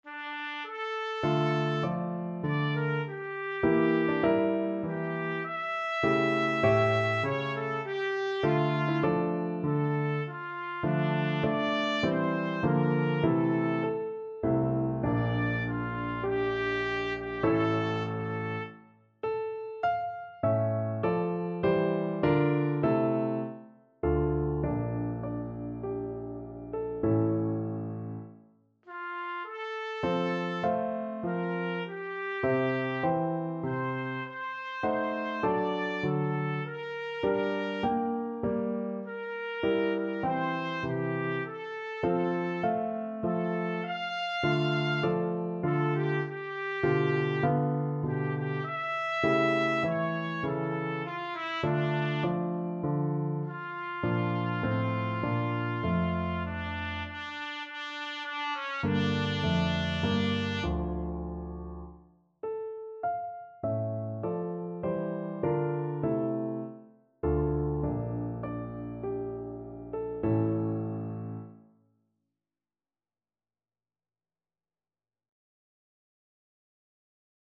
Free Sheet music for Trumpet
Trumpet
D minor (Sounding Pitch) E minor (Trumpet in Bb) (View more D minor Music for Trumpet )
4/4 (View more 4/4 Music)
Largo =c.100
Classical (View more Classical Trumpet Music)